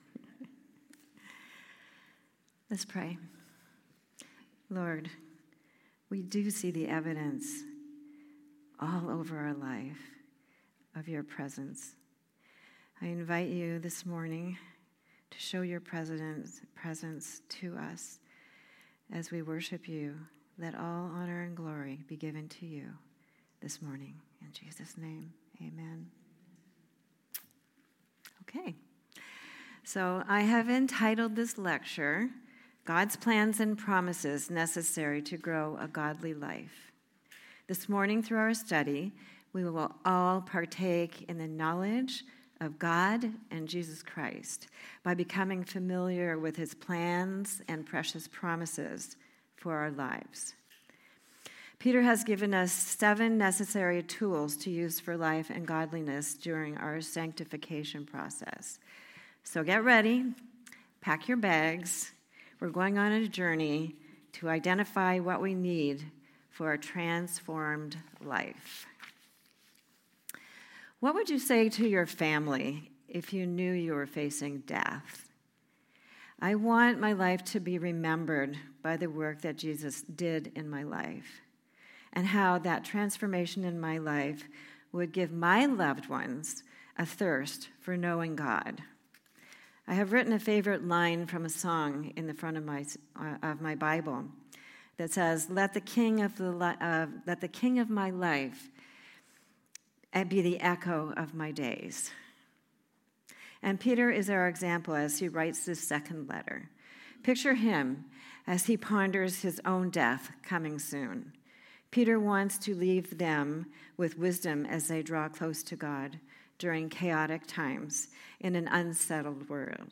Week 2 Message